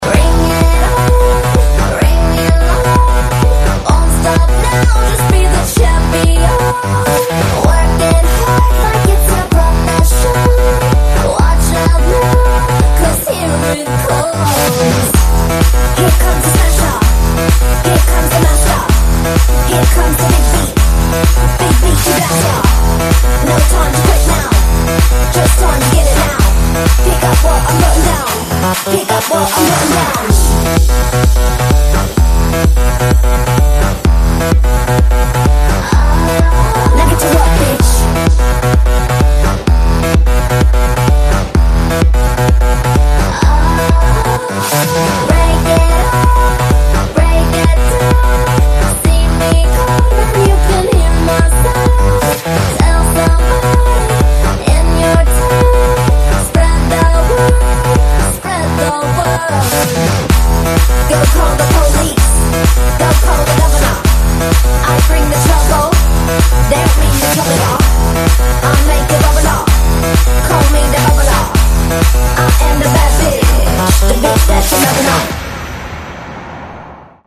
поп
громкие
женский вокал
зажигательные
Electronic